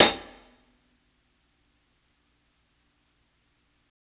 60s_HH_MED 2.wav